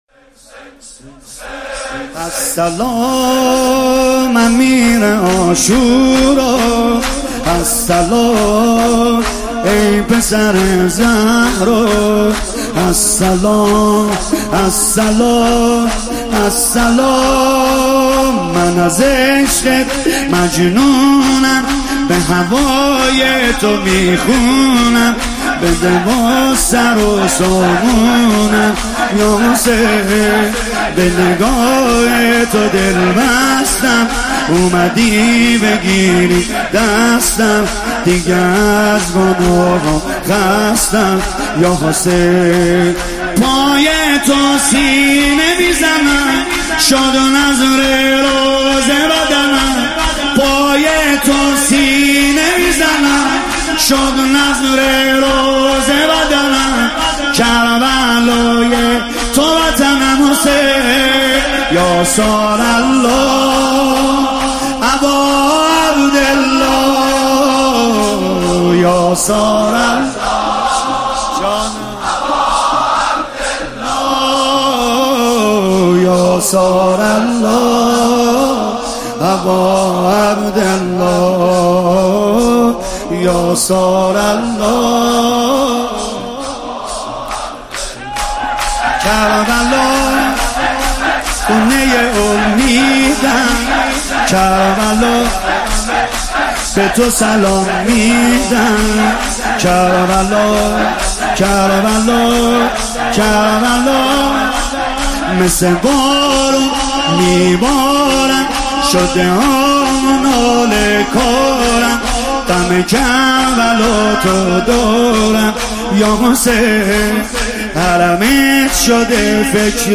شب ششم محرم97